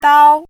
chinese-voice - 汉字语音库
dao1.mp3